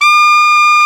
SAX A.MF D0Q.wav